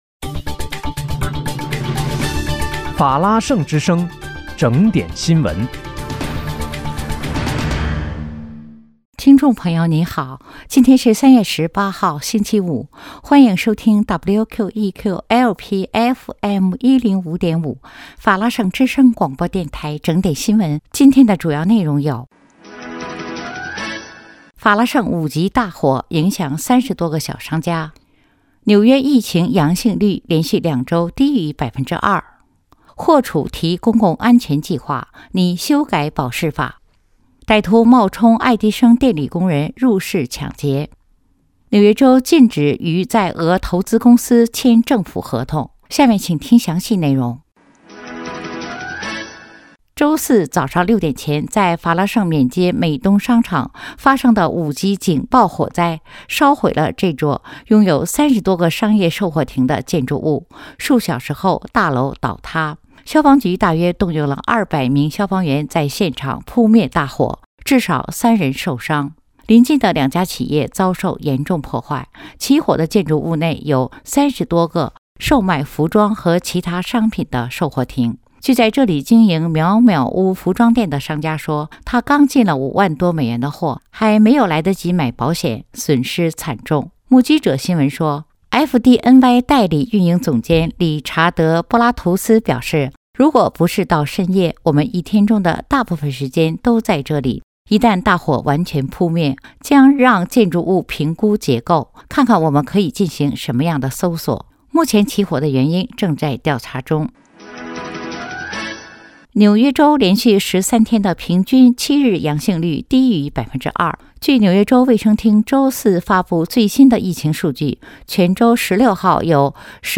3月18日（星期五）纽约整点新闻